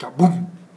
Explo_4.wav